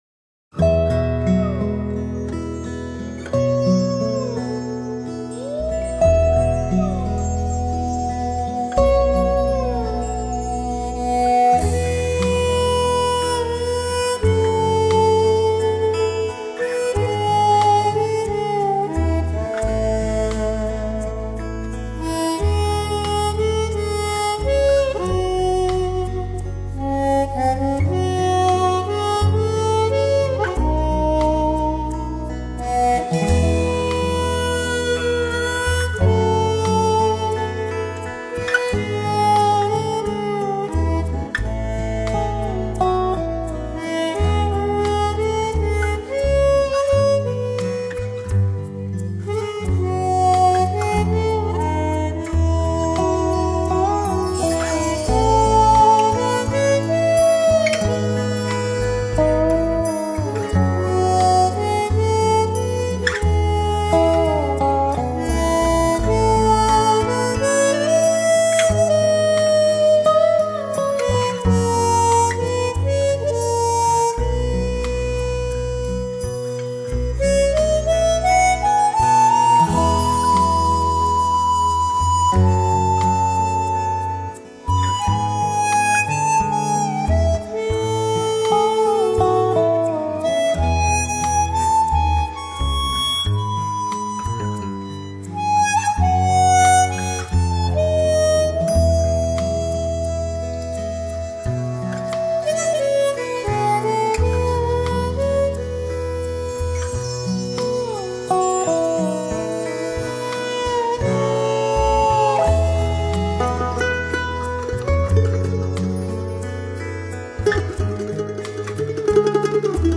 整體上的音樂風格偏向於鄉村音樂，演奏樂器包括常規鄉村音樂中必不可少的吉他、曼陀林、口琴、手風琴
利用多軌錄音合成起來，節奏優雅悠閒，極富感染力